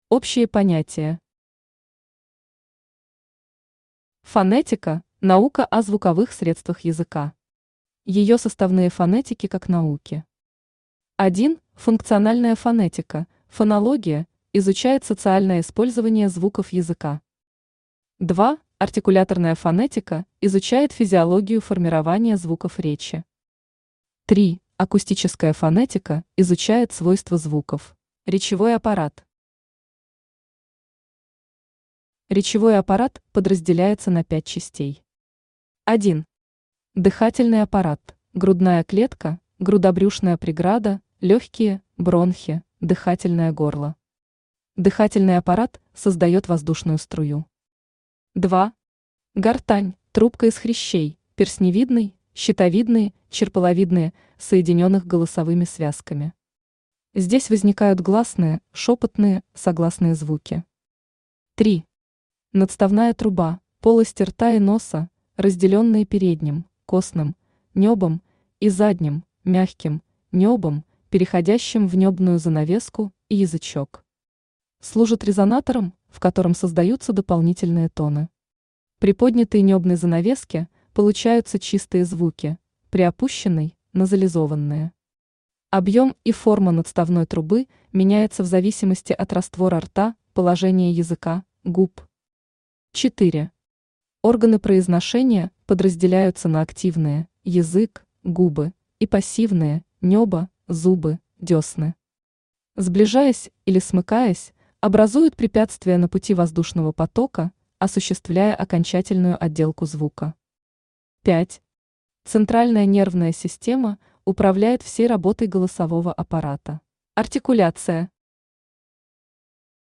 Аудиокнига Элементарная фонетика. Конспективное изложение | Библиотека аудиокниг
Конспективное изложение Автор Ирина Ивановна Тушева Читает аудиокнигу Авточтец ЛитРес.